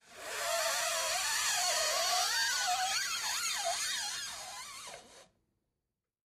Rubber Squeak: Slow Bys.